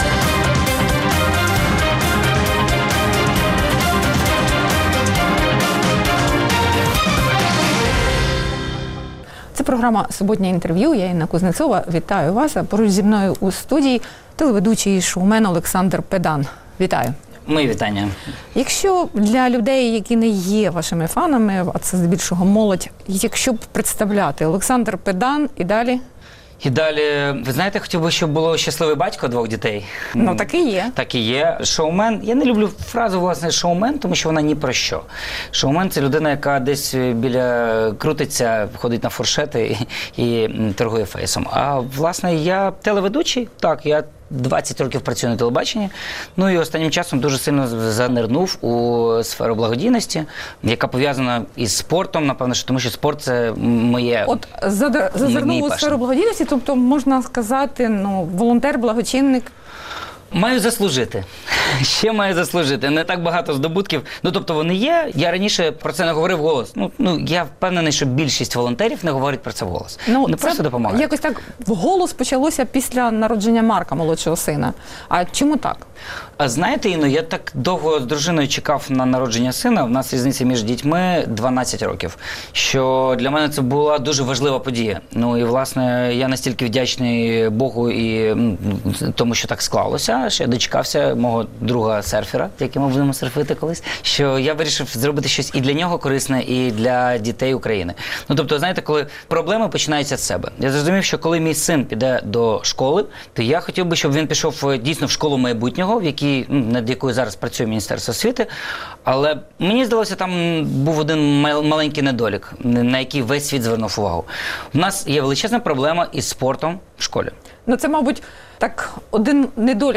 Суботнє інтерв’ю - Олександр Педан, телеведучий